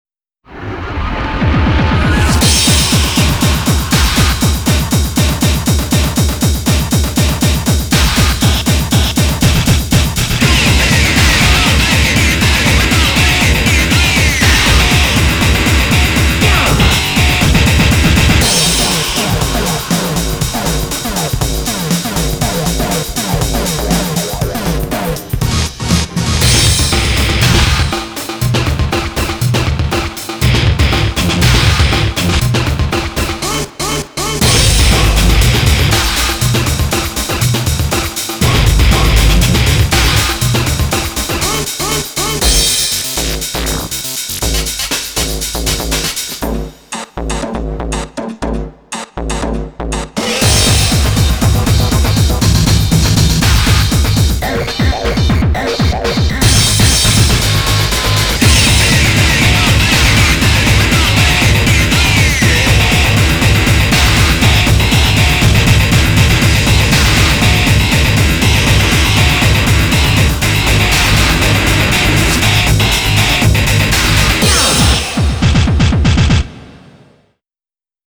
BPM120-240
Audio QualityPerfect (High Quality)
Genre: DIGITAL HARDCORE.